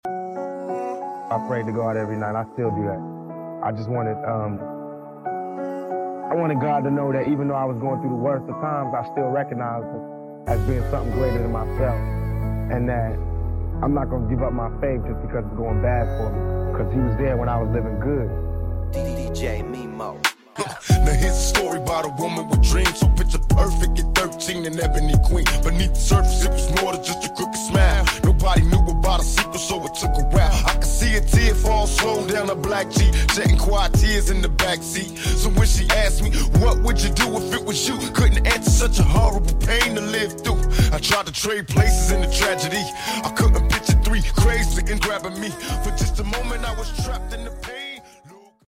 I had the honor of delivering remarks during the U.S. Southern Command change of command ceremony in Miami, where I officially relinquished command to Marine Corps Gen. John F. Kelly. It was a proud and meaningful moment, reflecting on the incredible work of our team and the strong partnerships we built throughout the region.